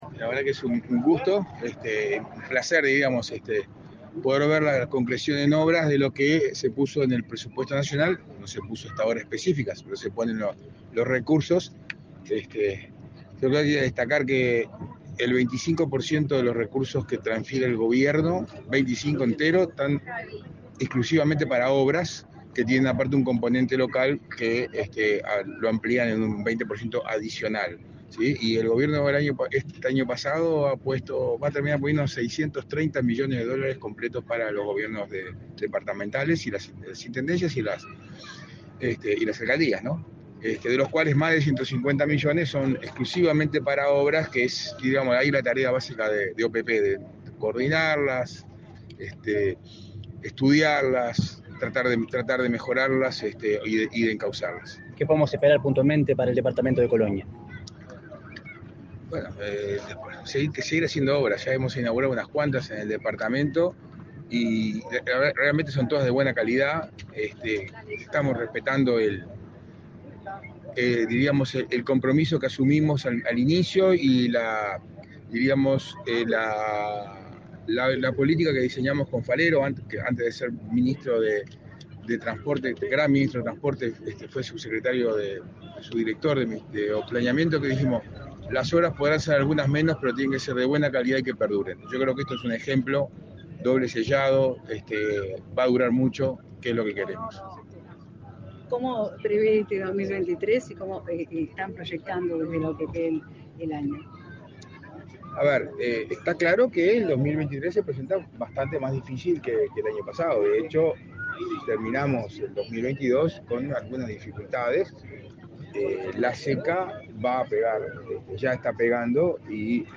Declaraciones a la prensa del director de la OPP, Isaac Alfie
Declaraciones a la prensa del director de la OPP, Isaac Alfie 01/03/2023 Compartir Facebook X Copiar enlace WhatsApp LinkedIn Tras participar en la inauguración de obras realizadas por la Oficina de Planeamiento y Presupuesto (OPP), la Intendencia de Colonia y el Municipio de Carmelo, este 28 de febrero, el director de la OPP, Isaac Alfie, realizó declaraciones a la prensa.